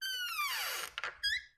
Creak
Door Open Close / Squeaks, Various; Door Squeaks 1